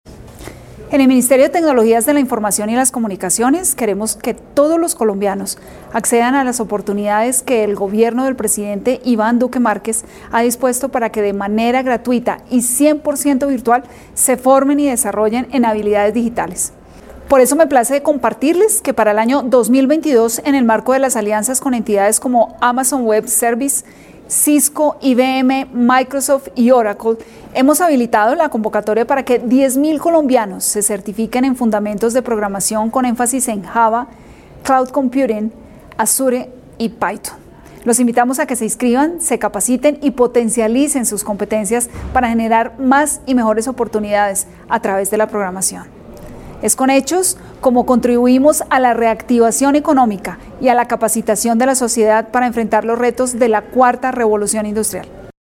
Declaración de la Ministra TIC, Carmen Ligia Valderrama Rojas.